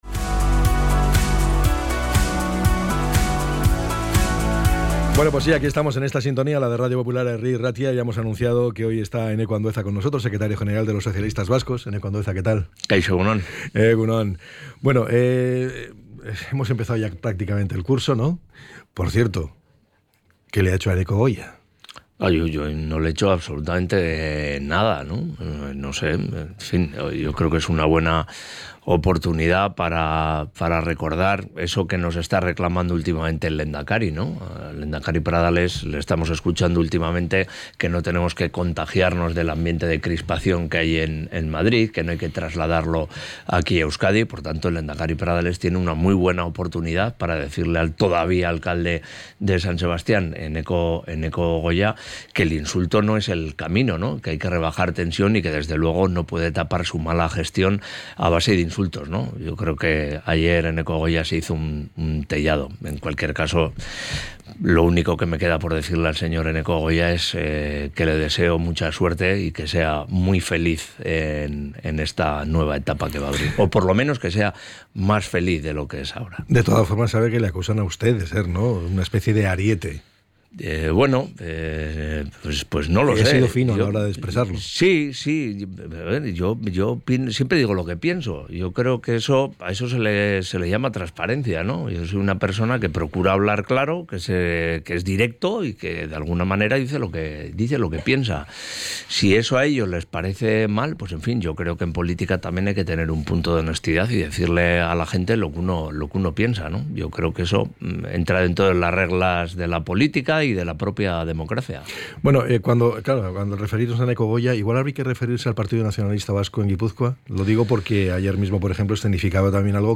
ENTREV.-ENEKO-ANDUEZA.mp3